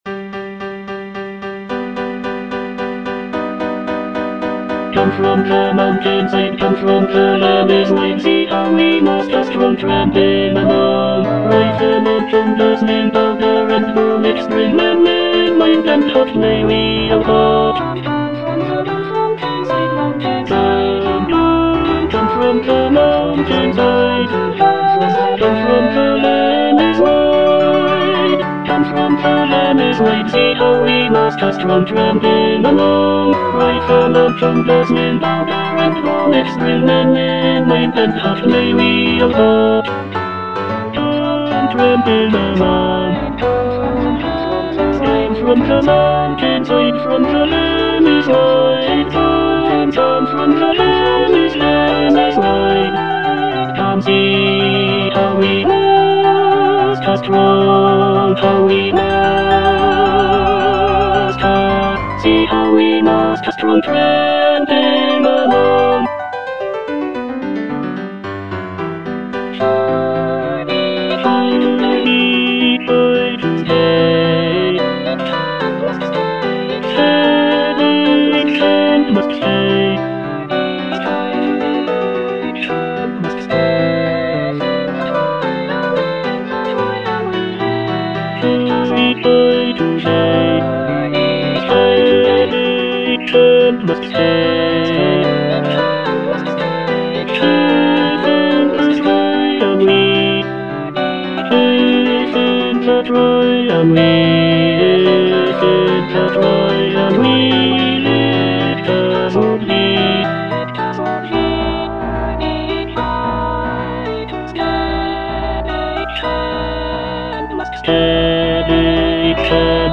E. ELGAR - FROM THE BAVARIAN HIGHLANDS The marksmen (tenor II) (Emphasised voice and other voices) Ads stop: auto-stop
The music captures the essence of the picturesque landscapes and folk traditions of the area, with lively melodies and lush harmonies.